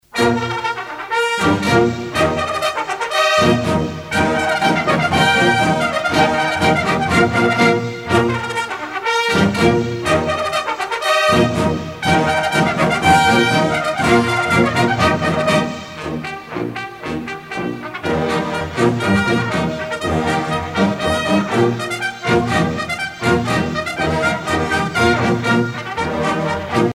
à marcher
militaire